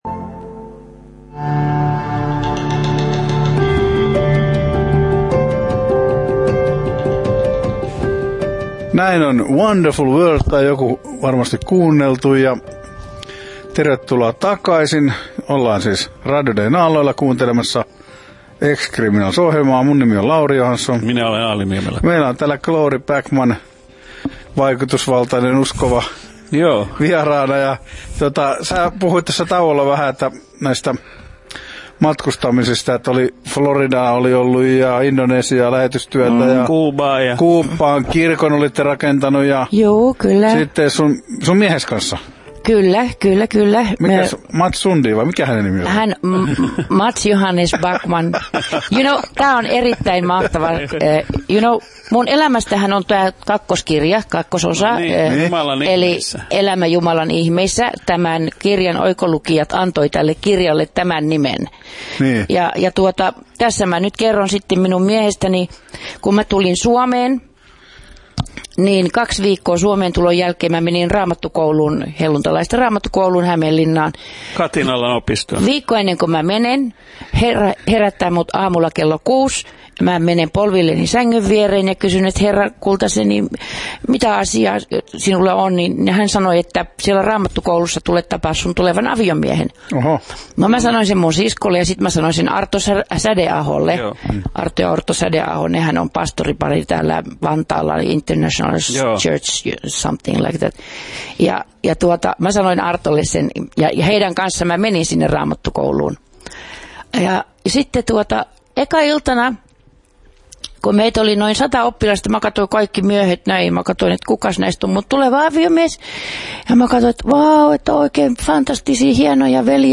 Kuuntele koskettava haastattelu: